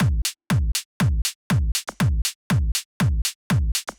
Drumloop 120bpm 08-C.wav